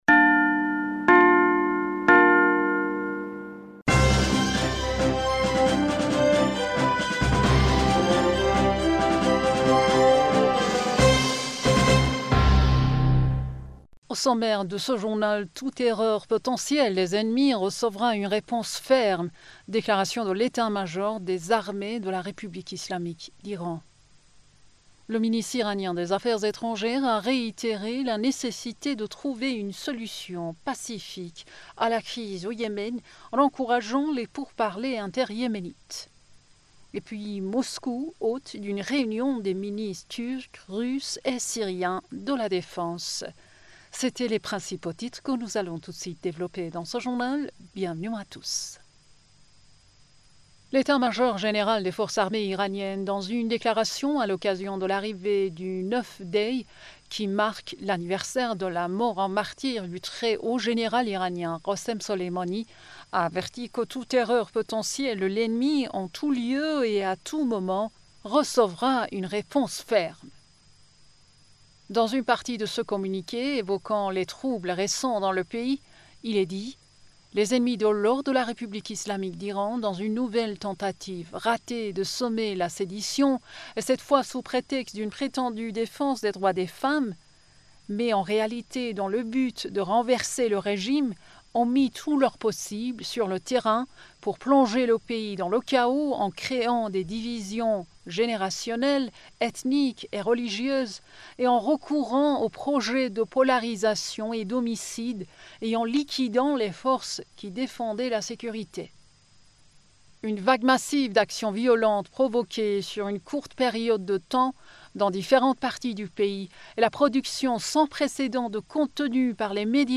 Bulletin d'information du 29 Décembre